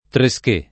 [ tre S k $+ ]